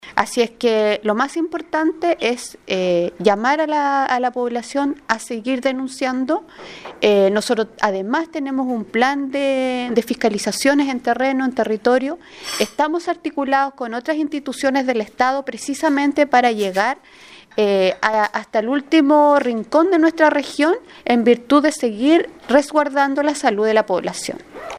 Frente a estas situaciones, la Seremi de Salud Los Lagos, Karin Solis, expresó que regularmente se inician sumarios sanitarios cuando se descubre empresas que no poseen los permisos sanitarios para su funcionamiento o no respetan las condiciones higiénicas